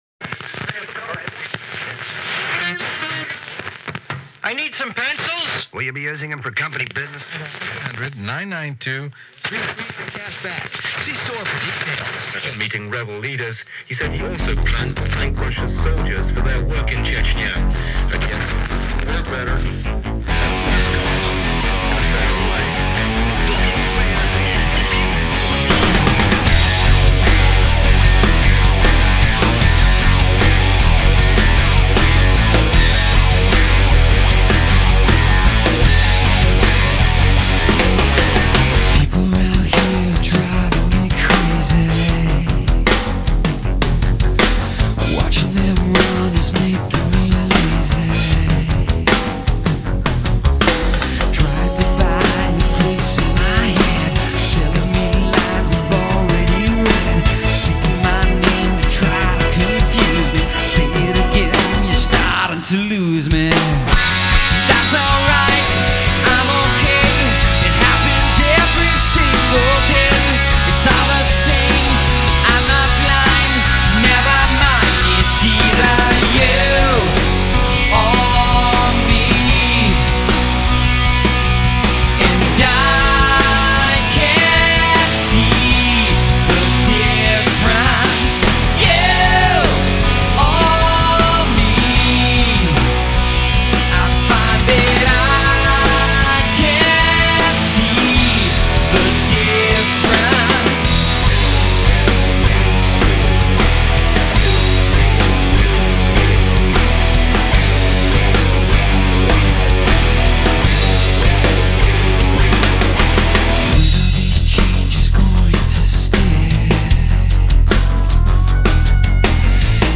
Progressive metal